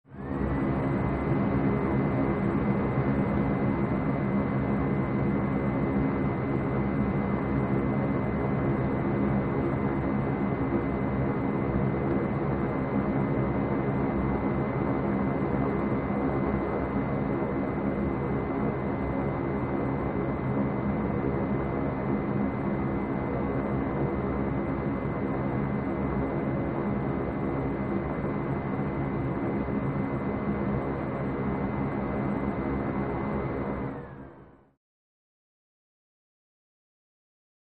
Low End Air Hiss Through Vacuum Tube With Motor Run